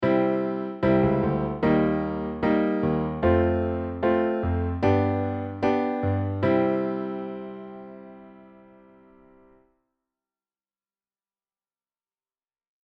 彼のお仕事振りは、クセが強いのが特徴ですね。
Ａの方は個性的力強い